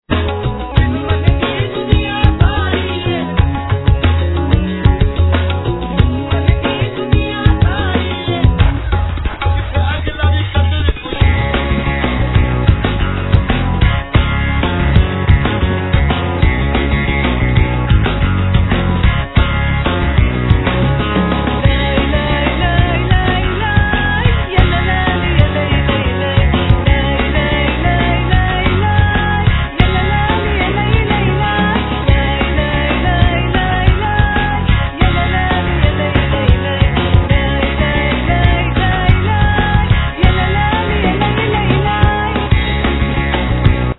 Vocal
Arrangements, Samples, Keys, Guitar, Bass, Saz, Percussions
Drums
Tabla, Disgeridoo, Percussions, Aliquot singing